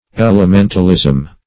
Search Result for " elementalism" : The Collaborative International Dictionary of English v.0.48: Elementalism \El`e*men"tal*ism\ (-[i^]z'm), a. The theory that the heathen divinities originated in the personification of elemental powers.
elementalism.mp3